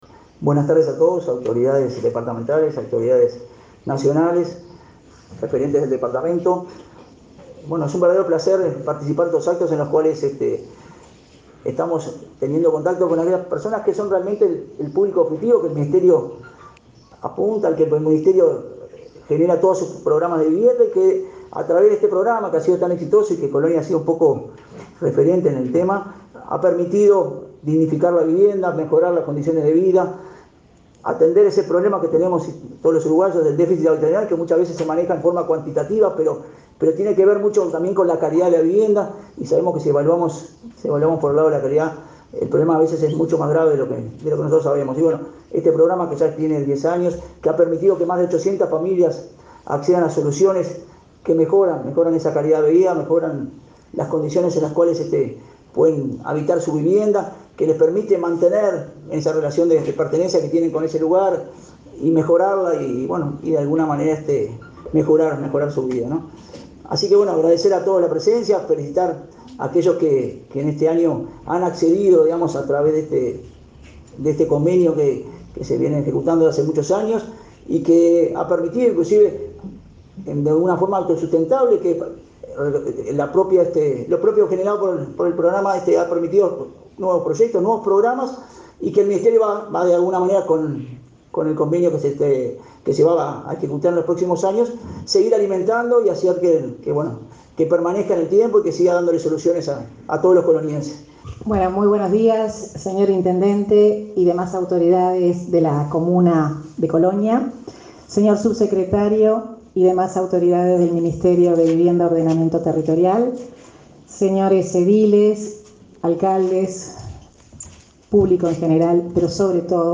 Declaraciones de autoridades de Vivienda, al entregar préstamos en Colonia
Declaraciones de autoridades de Vivienda, al entregar préstamos en Colonia 08/09/2021 Compartir Facebook X Copiar enlace WhatsApp LinkedIn La ministra de Vivienda y Ordenamiento Territorial, Irene Moreira, y el director nacional de Vivienda, Jorge Ceretta, participaron, este 8 de setiembre, en la entrega de préstamos para mejora de vivienda en Colonia. En el acto, efectuaron una conferencia de prensa.